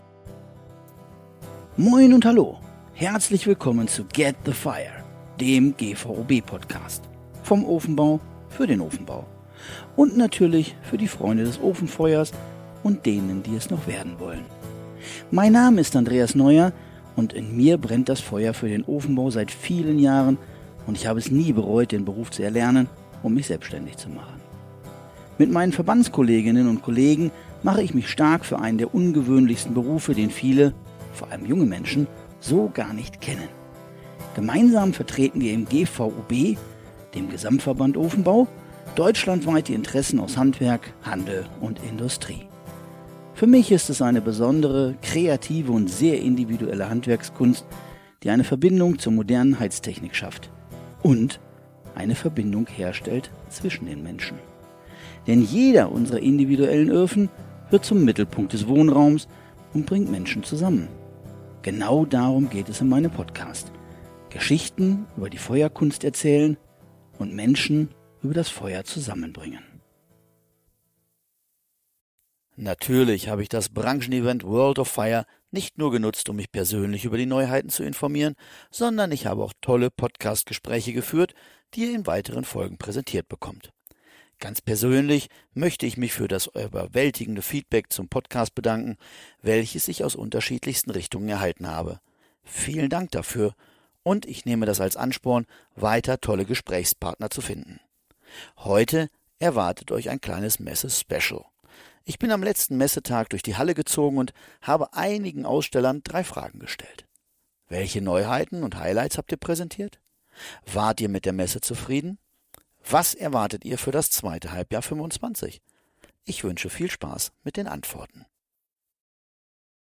Auf der World of Fireplaces-Messe in Leipzig habe ich die Gelegenheit genutzt und eine kleine Umfrage unter einigen Ausstellern durchgeführt.